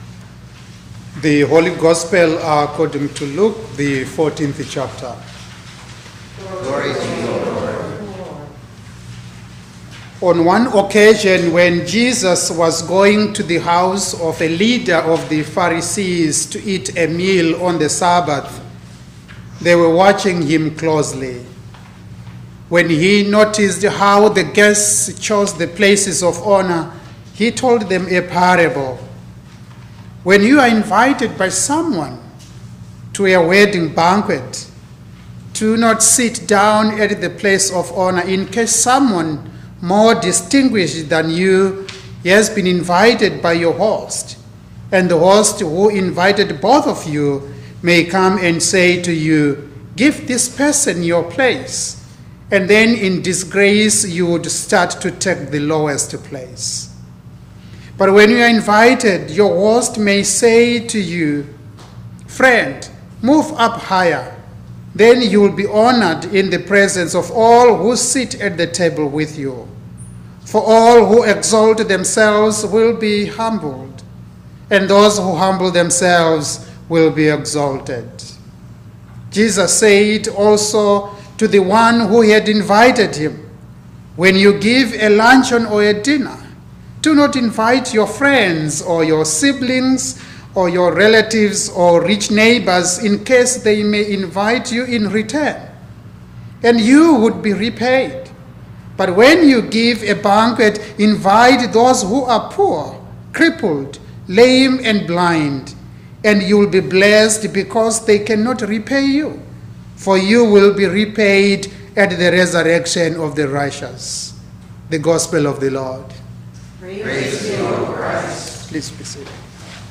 Sermon for the Twelfth Sunday after Pentecost 2025